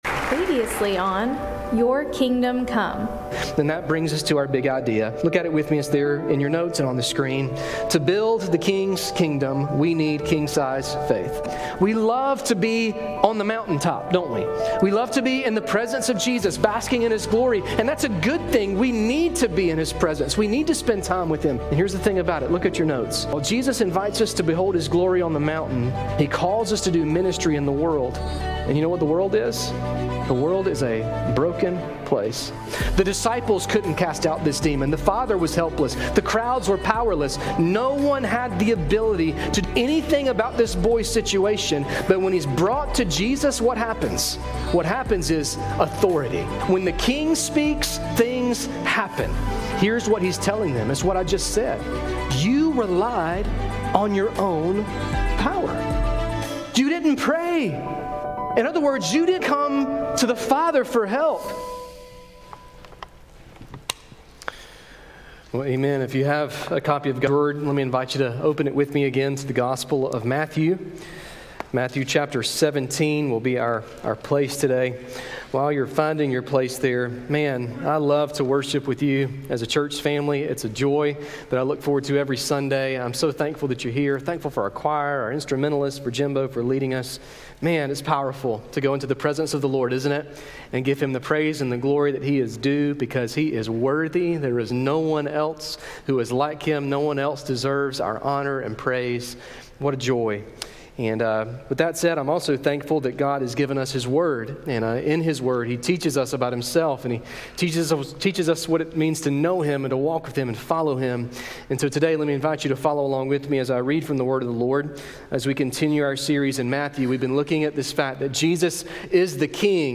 A message from the series "Your Kingdom Come."